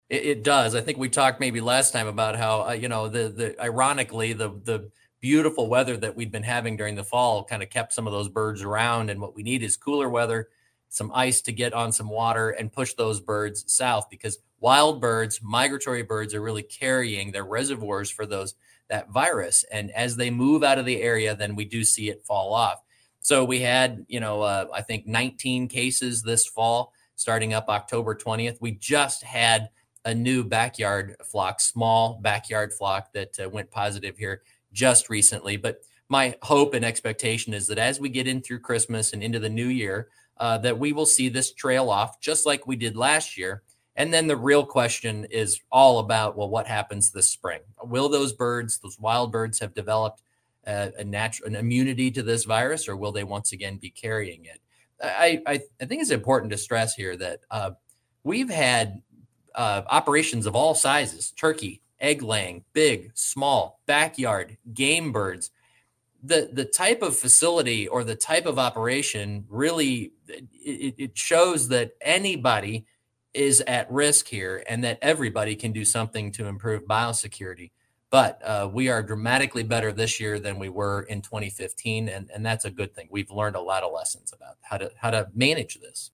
Iowa Ag Secretary Mike Naig talks about the situation as we approach the colder temps of winter and the hope that the outbreak will die down.